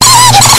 Worms speechbanks
Revenge.wav